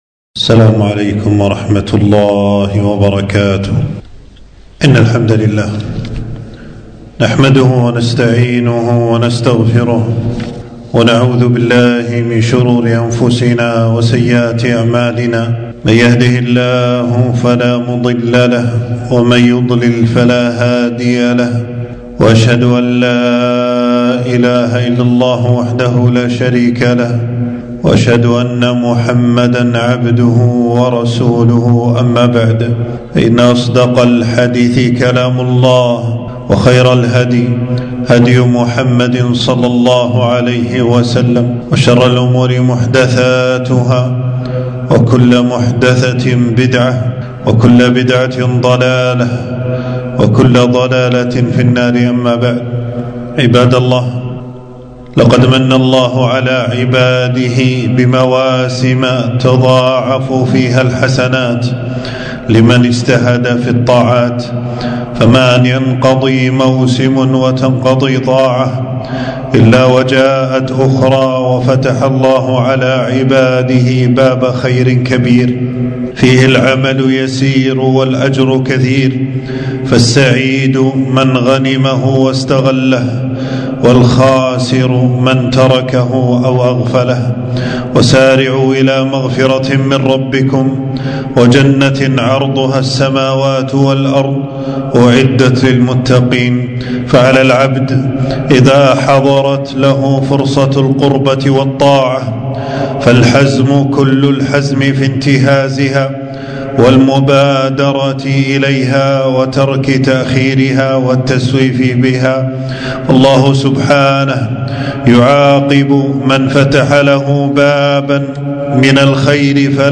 • خطبة بعنوان: شعبان شهر يغفل الناس عنه.